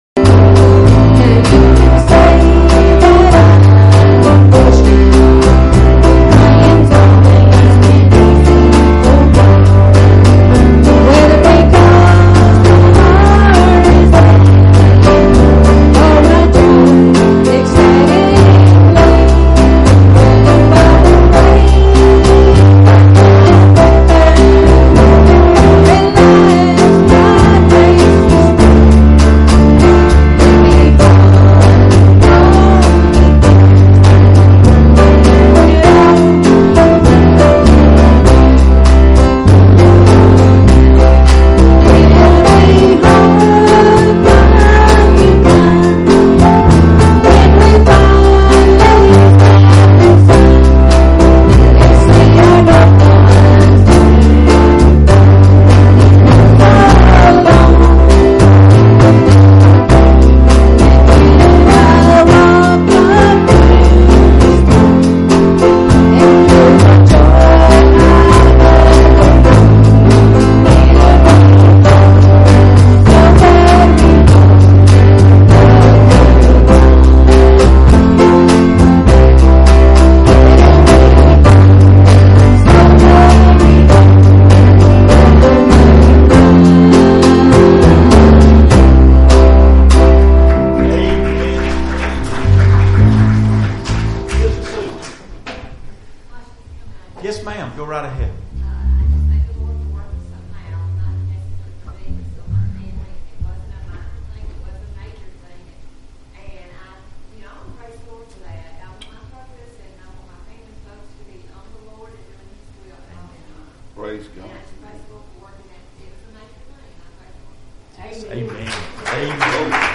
Passage: Hebrews 3:4-6 Service Type: Wednesday Evening Services